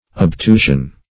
Search Result for " obtusion" : The Collaborative International Dictionary of English v.0.48: Obtusion \Ob*tu"sion\, n. [L. obtusio, from obtundere to blunt.